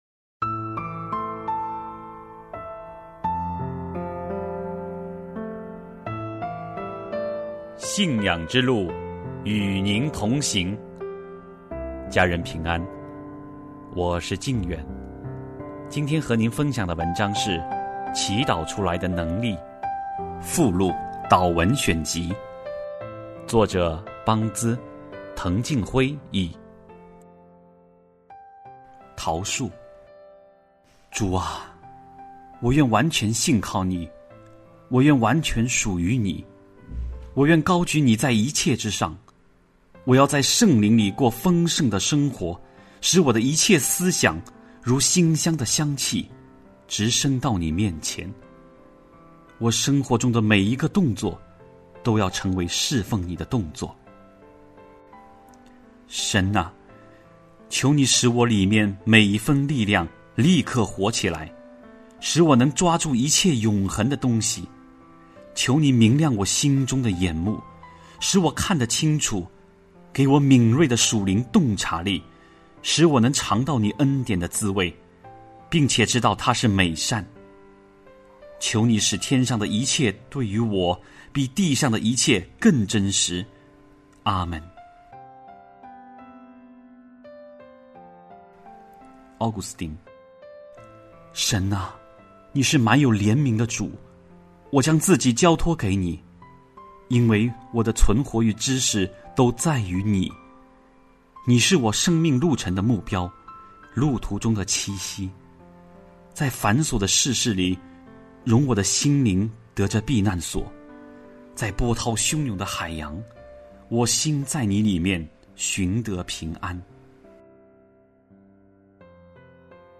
首页 > 有声书 | 灵性生活 | 祈祷出来的能力 > 祈祷出来的能力:附录-导文选集